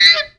FAT OB.wav